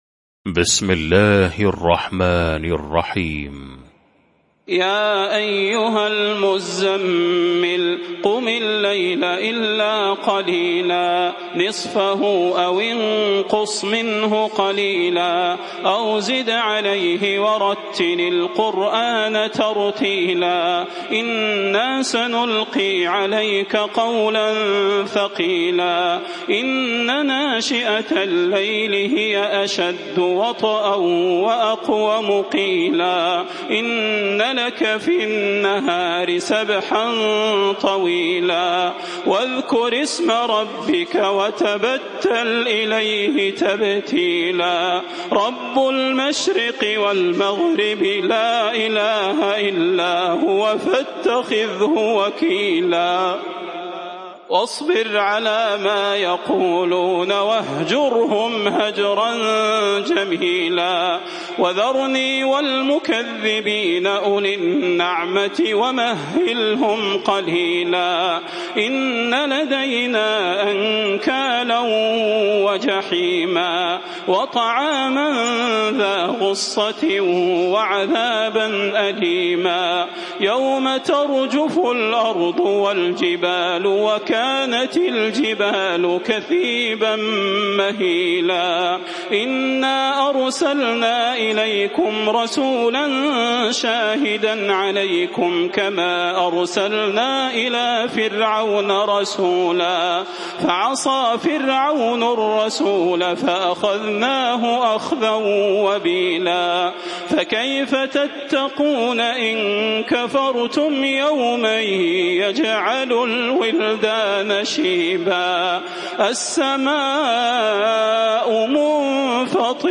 المكان: المسجد النبوي الشيخ: فضيلة الشيخ د. صلاح بن محمد البدير فضيلة الشيخ د. صلاح بن محمد البدير المزمل